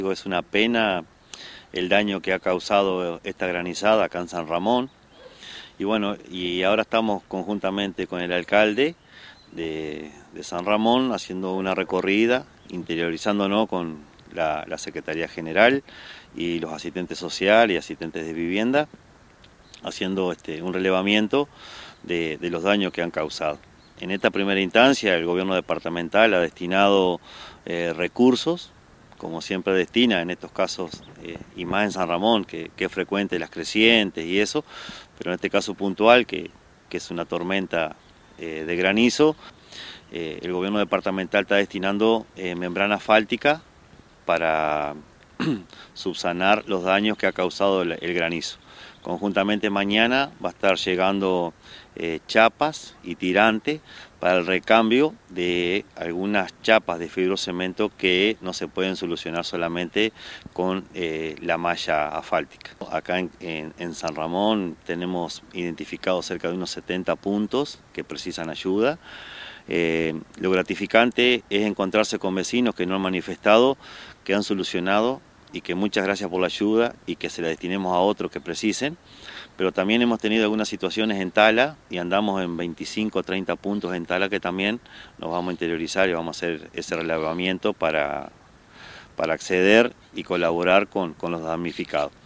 El Intendente en funciones, Alejandro Alberro, señaló que están haciendo un relevamiento de los daños causados por el temporal y están evaluando, conjuntamente con la Secretaría General, las asistentes sociales y de vivienda para las personas afectadas. En ese sentido, indicó que la Intendencia volcará recursos para solucionar las perforaciones en techos de fibrocemento, destinará membrana asfáltica para subsanar los daños causados por el granizo y chapas y tirantes para el recambio.